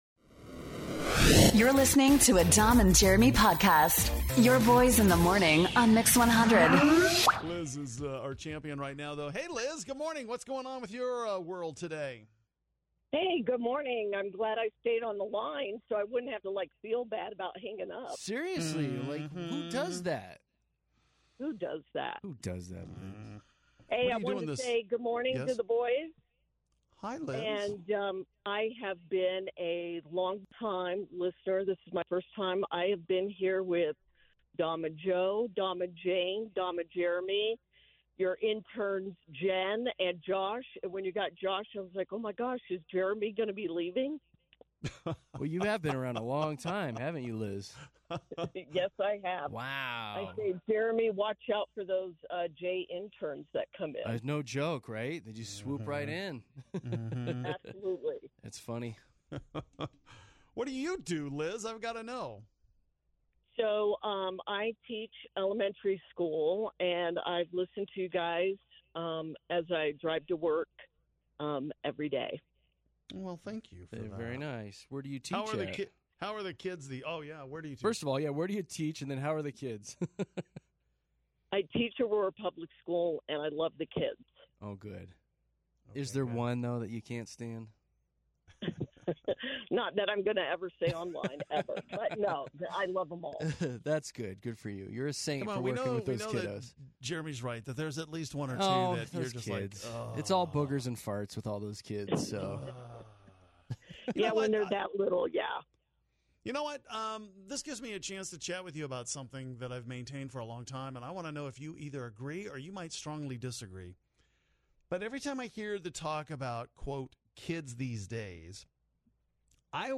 We talk to long time listeners who are calling in for the first time.